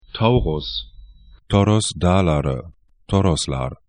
Taurus 'taurʊs Toros dağları